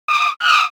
propellersscreetch.wav